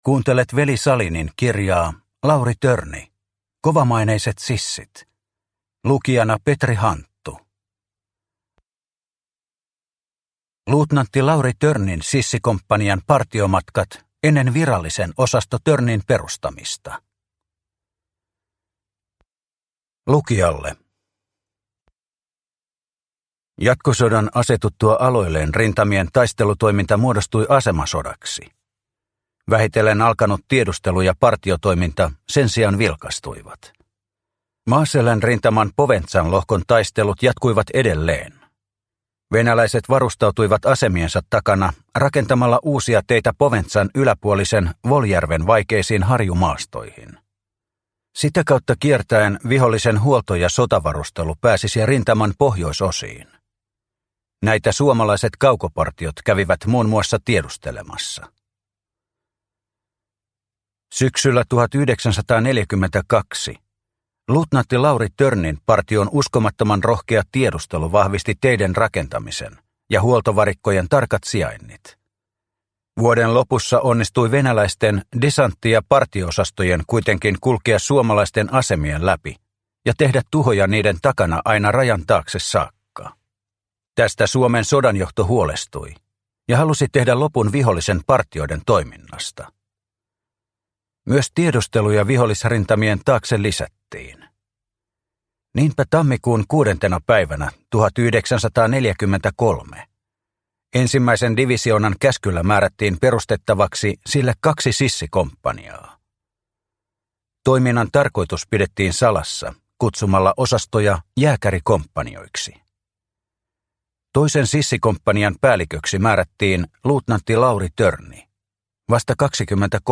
Lauri Törni – Ljudbok – Laddas ner